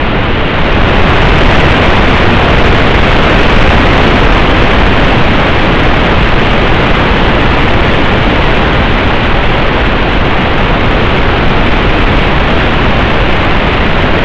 There’s Music Hidden in This Sound:
wind-whisper-1.wav